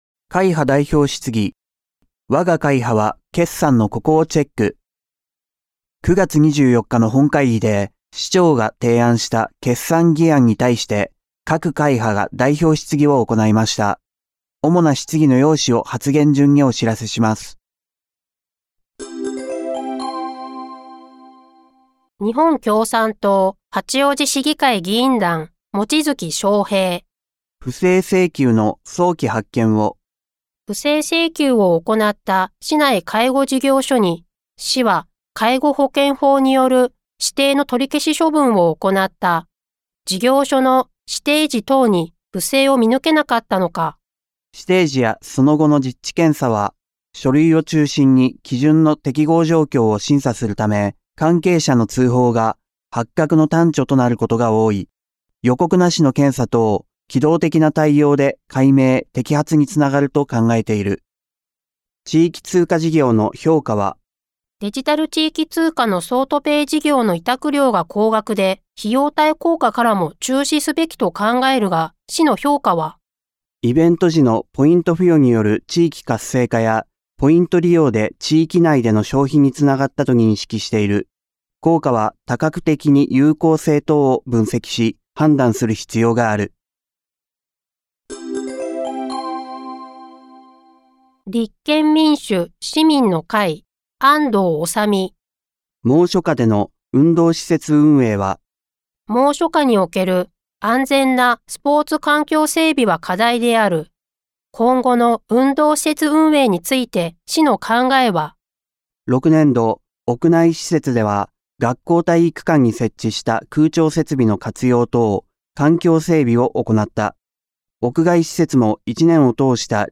「声の市議会だより」は、視覚に障害のある方を対象に「八王子市議会だより」を再編集し、音声にしたものです。